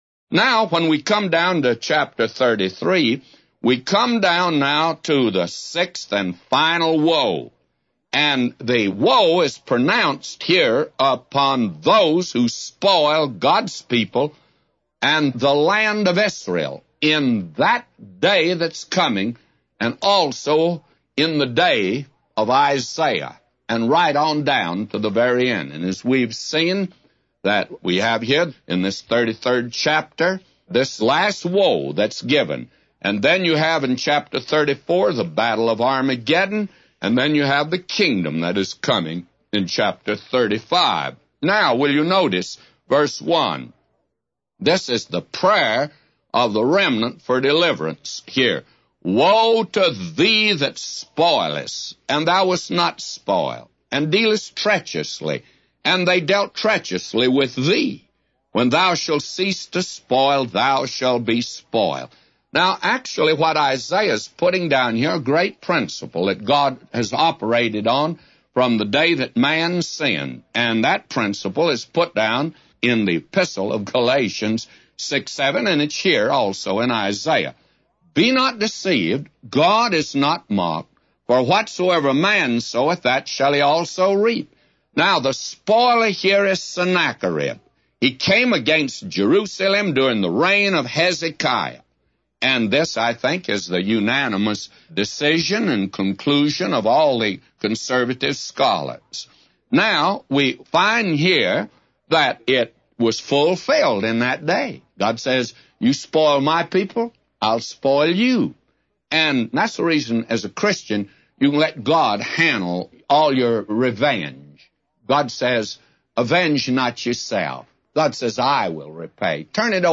A Commentary By J Vernon MCgee For Isaiah 33:1-999